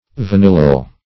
Search Result for " vanillyl" : The Collaborative International Dictionary of English v.0.48: Vanillyl \Va*nil"lyl\, n. [Vanillic + -yl.]